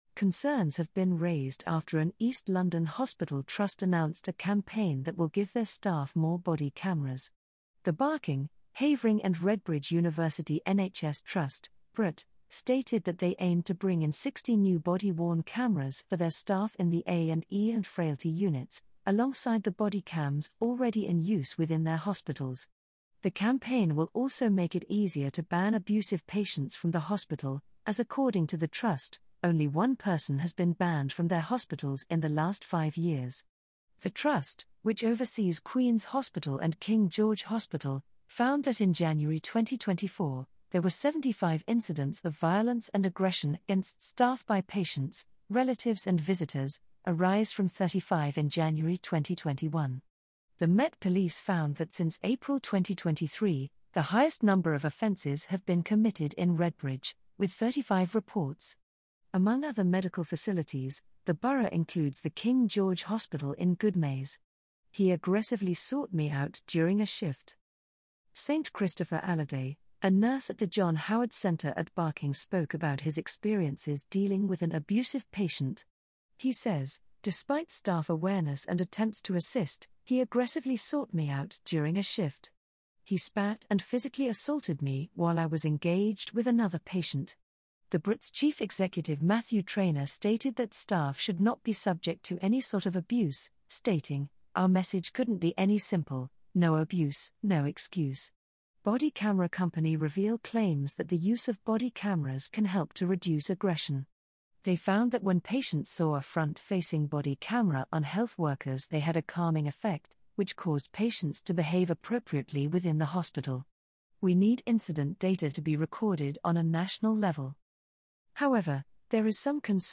Listen to this article – powered by AI: